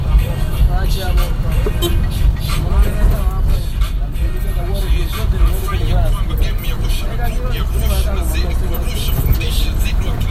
Men det finns både massor med mc-taxi, vanliga taxibilar och massor med minibussar med plast för +15 pers som stannar där man vill och rullar jämnt och kostar mycket lite. Ljudmiljön ombord på minibuss idag…